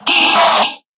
Minecraft Version Minecraft Version snapshot Latest Release | Latest Snapshot snapshot / assets / minecraft / sounds / mob / endermen / scream1.ogg Compare With Compare With Latest Release | Latest Snapshot
scream1.ogg